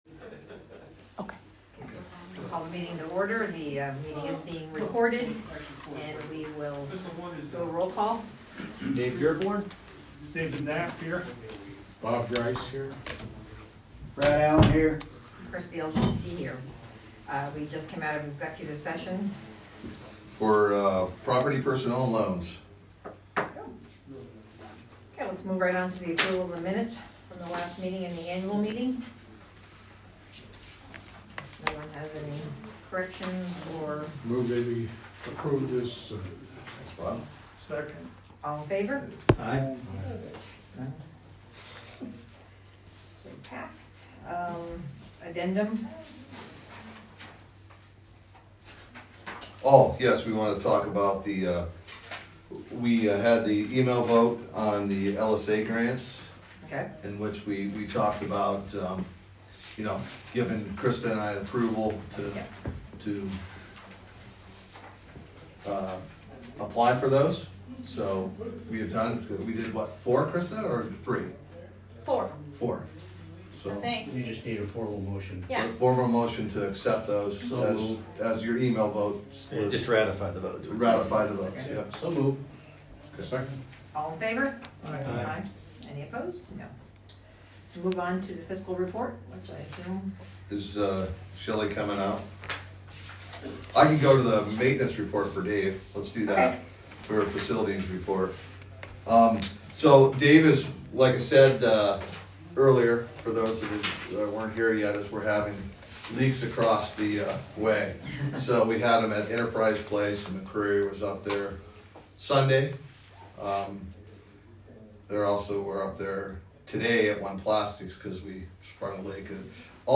Meeting Audio